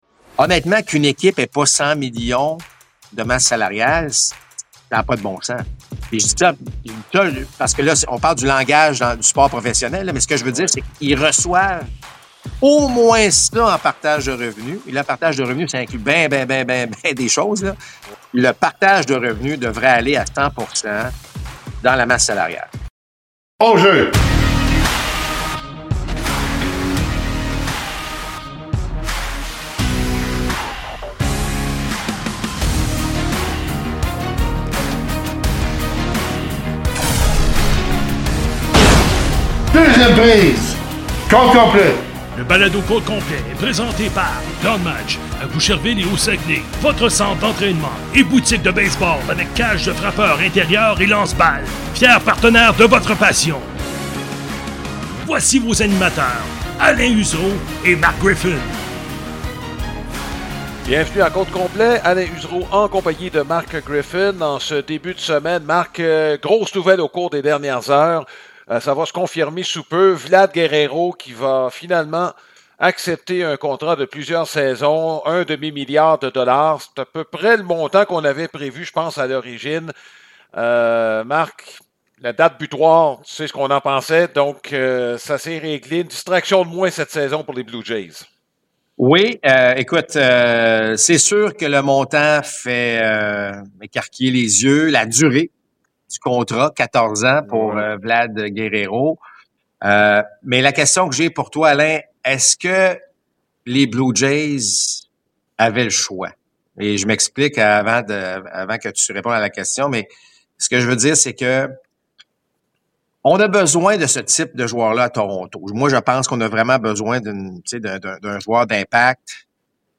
Compte complet présente des entretiens avec des personnalités reliées au baseball.